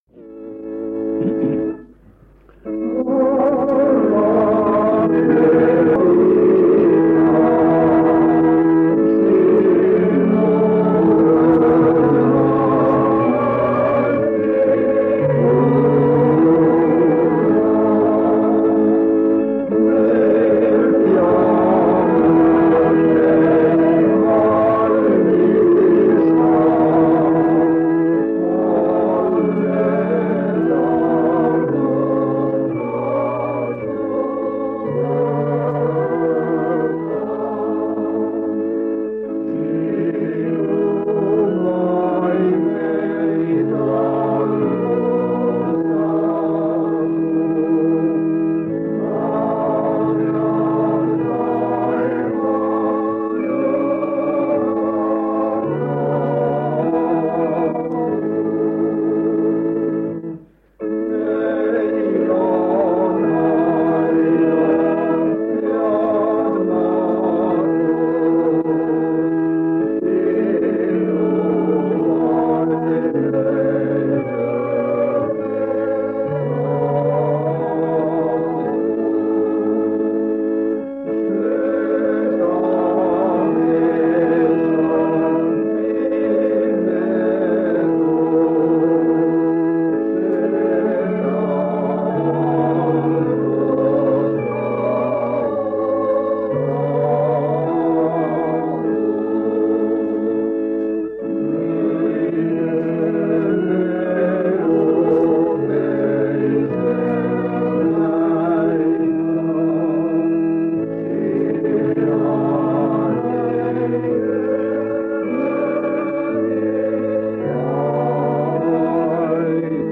On 1977 aasta kevad. Paide adventkirikus toimub
Täpsemaid kuupäevi pole teada ning jagasin lintmaki lintidele talletatu kuueks päevaks.